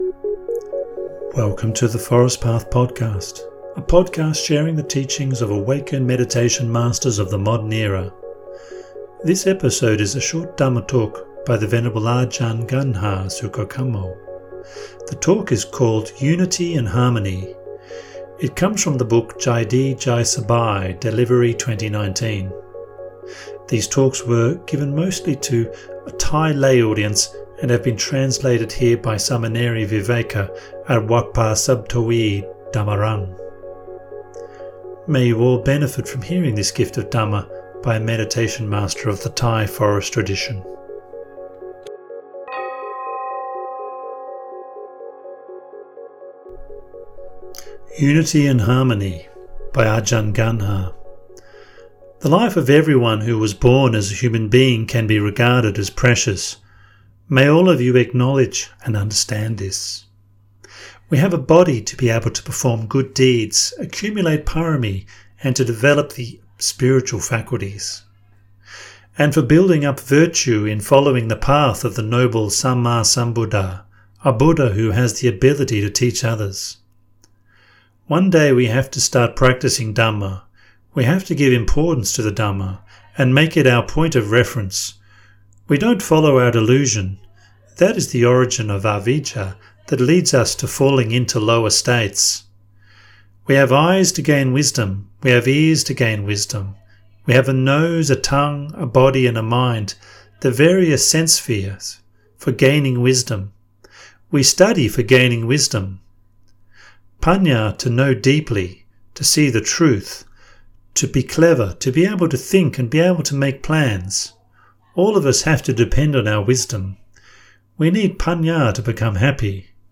This episode is a short dhamma talk